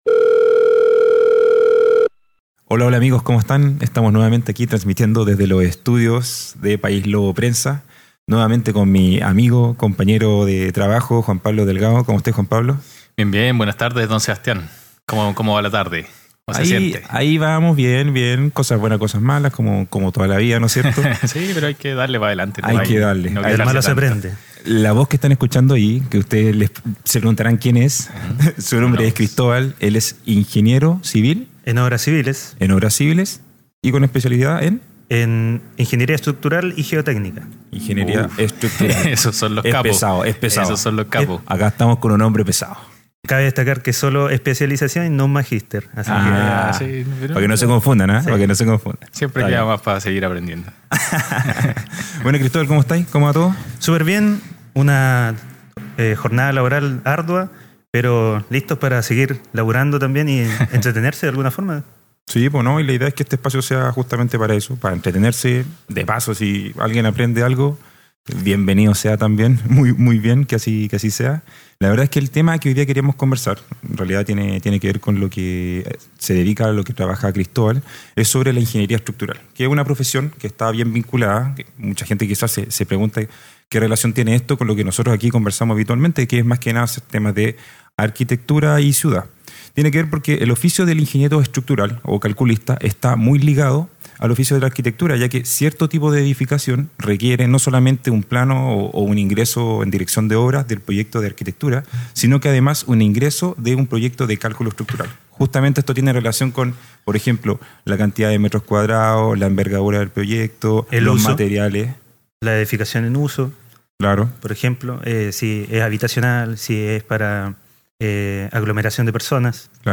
En esta ocasión conversamos